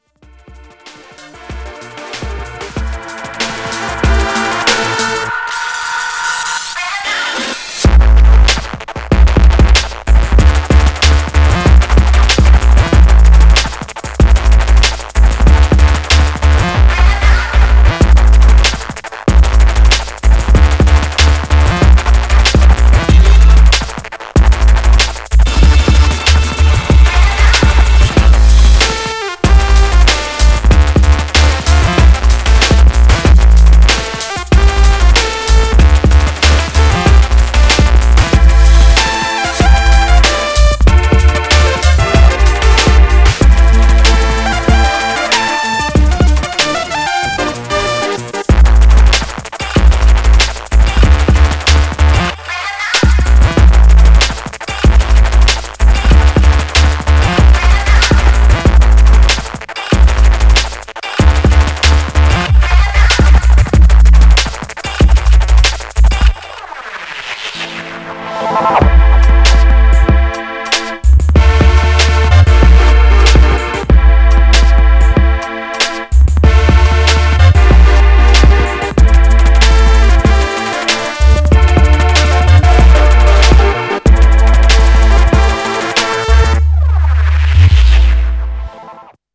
Styl: Dub/Dubstep, Breaks/Breakbeat